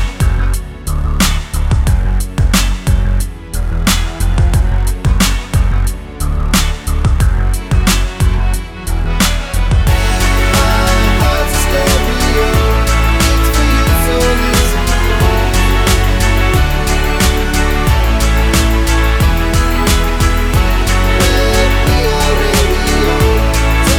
no Backing Vocals Dance 3:36 Buy £1.50